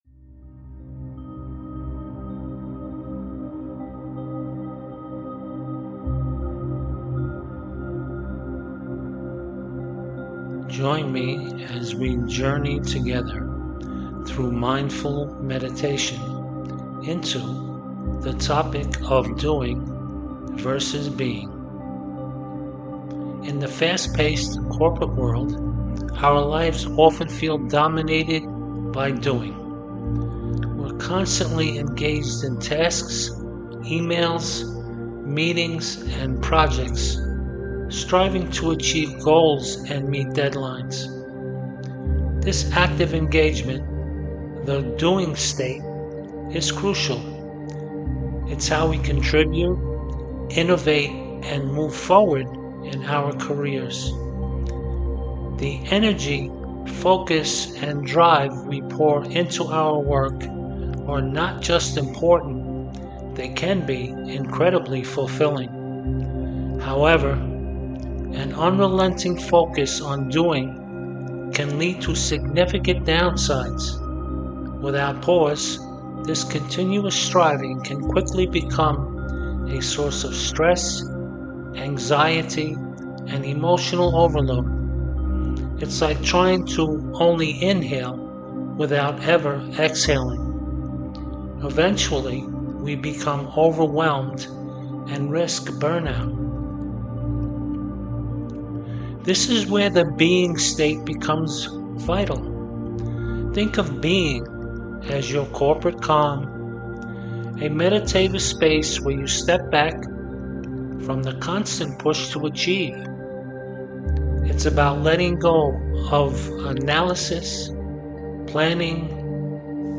DOING-vs-BEING-Guided-Meditation.mp3